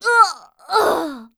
cleric_f_voc_die_a.wav